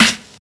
07_Snare_08_SP.wav